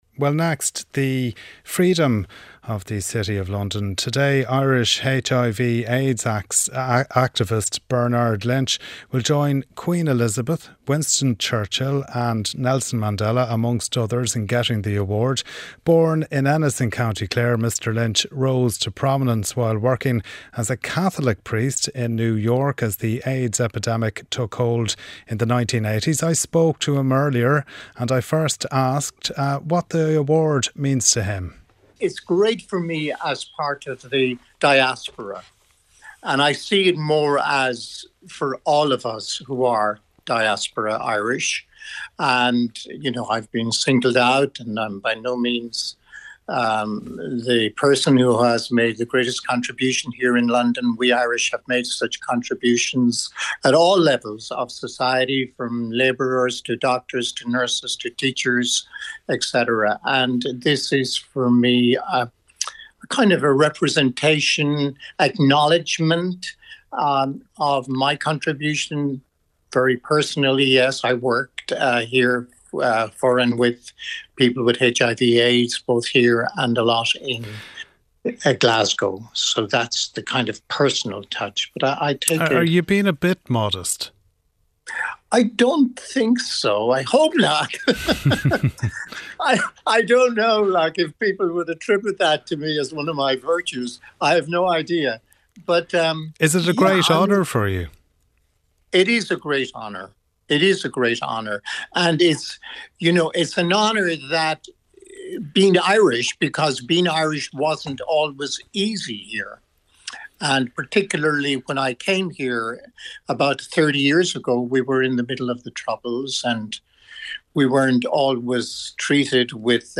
News, sport, business and interviews. Presented by Rachael English.